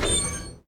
train-brake-screech-3.ogg